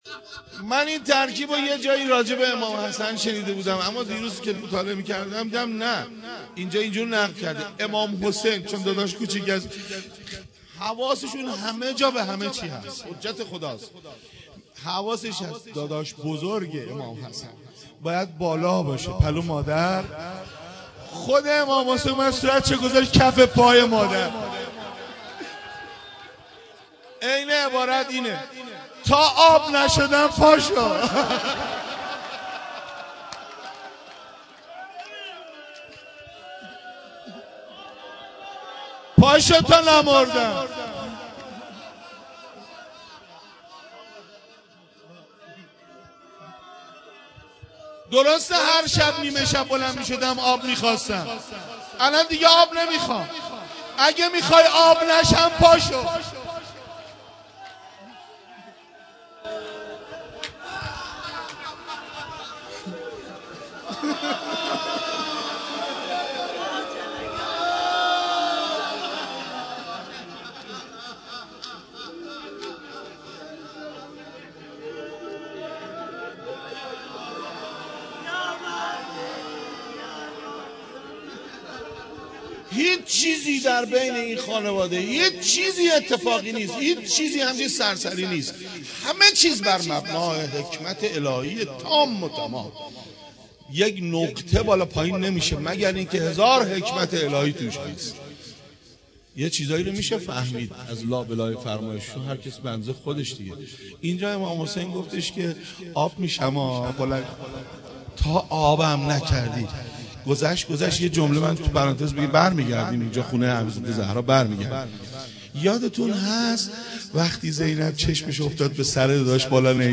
دانلود مداحی شرمنده زهرا - دانلود ریمیکس و آهنگ جدید
روضه شهادت مظلومانه حضرت زهرا(س)